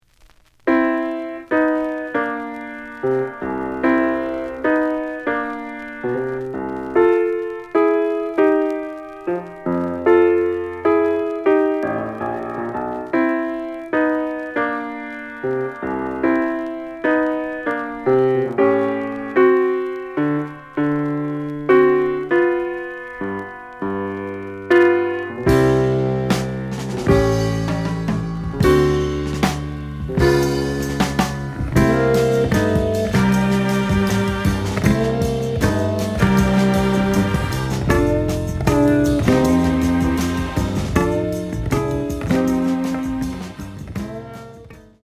The audio sample is recorded from the actual item.
●Genre: Jazz Funk / Soul Jazz
Slight noise on both sides.